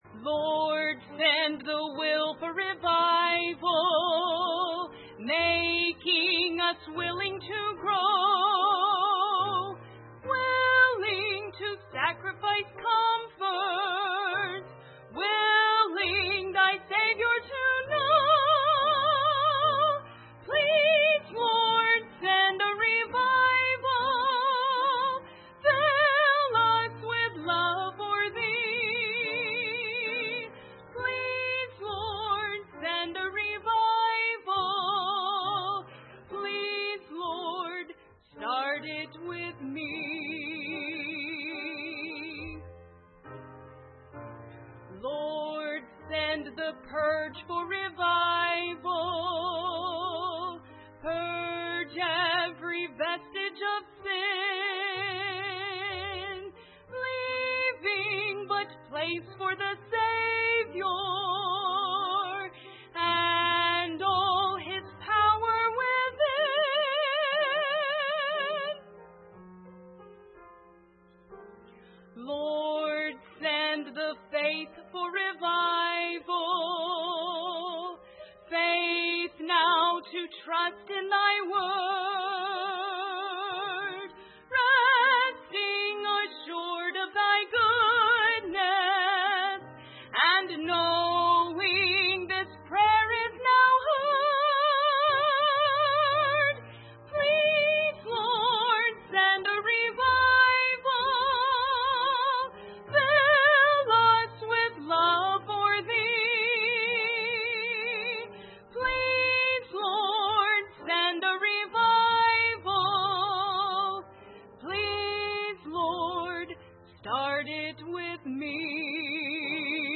2010 Winter Revival Service Type: Revival Service Preacher